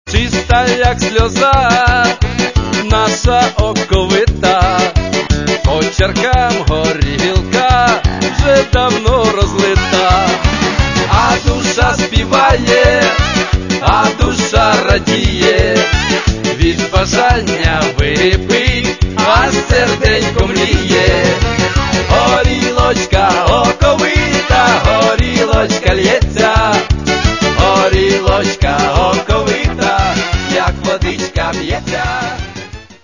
Каталог -> MP3-CD -> Эстрада
Чтобы эти самые развлечения происходили весело и живенько.